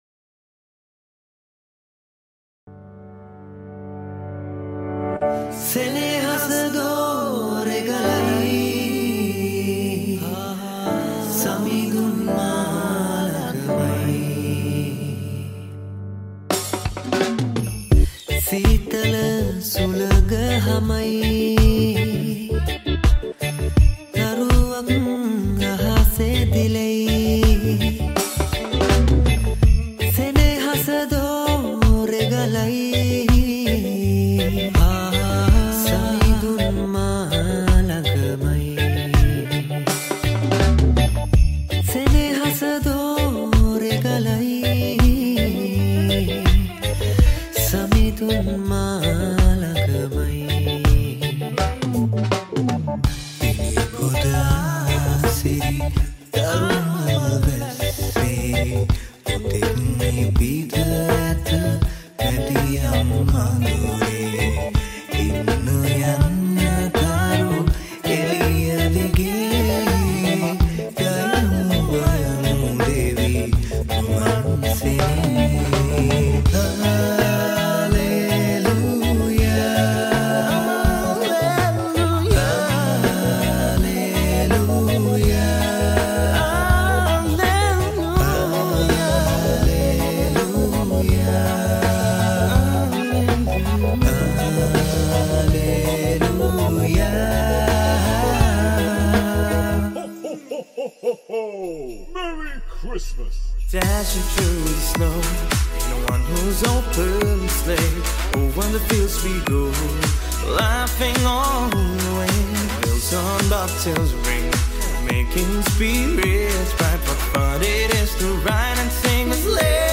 high quality remix
Covers